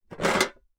Metal_10.wav